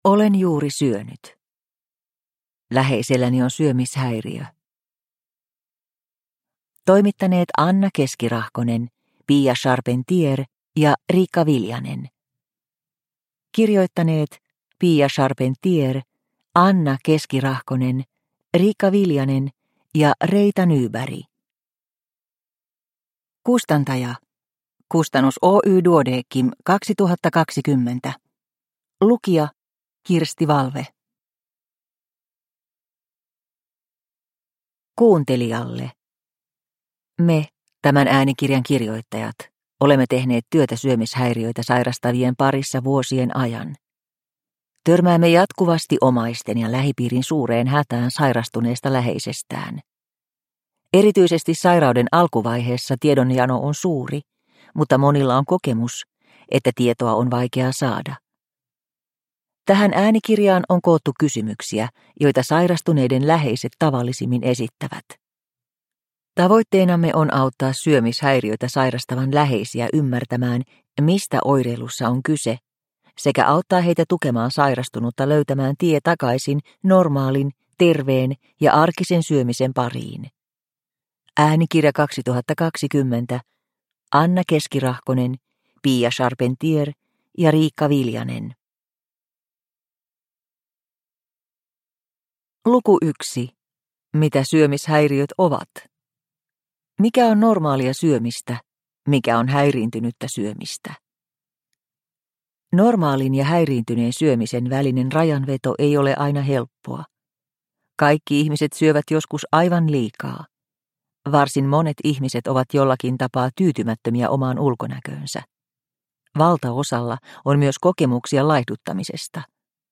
Olen juuri syönyt – Ljudbok – Laddas ner